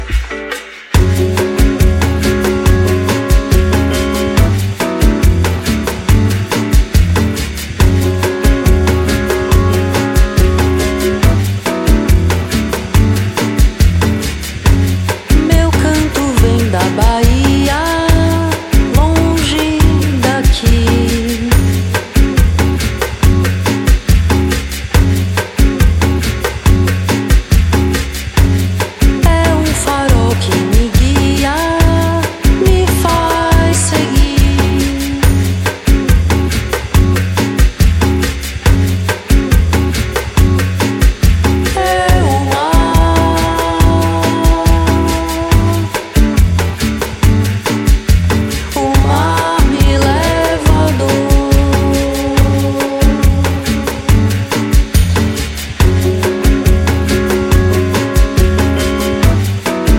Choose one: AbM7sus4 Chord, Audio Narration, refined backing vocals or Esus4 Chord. refined backing vocals